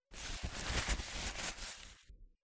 paper_handling.mp3